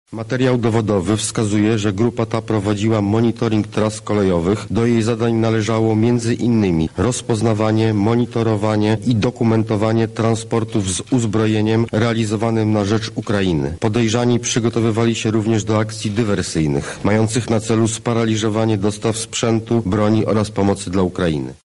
-mówi Mariusz Kamiński.